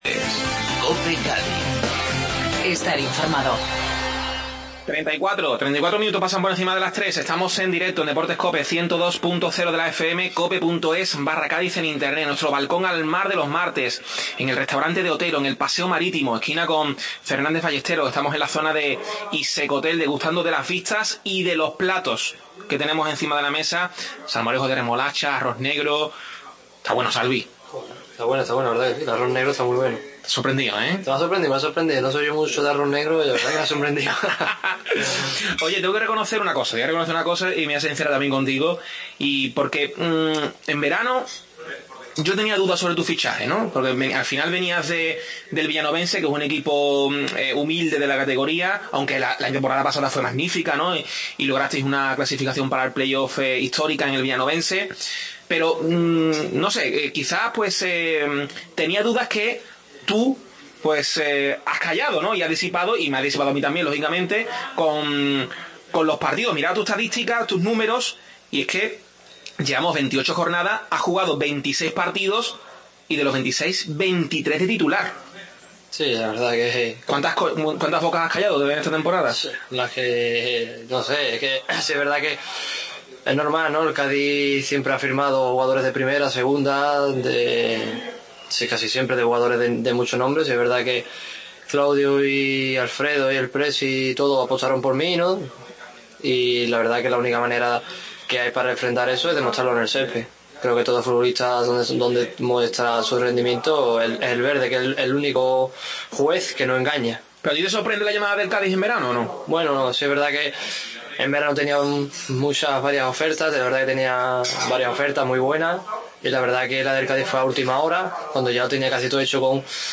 Segunda parte de la tertulia desde el Restaurante De Otero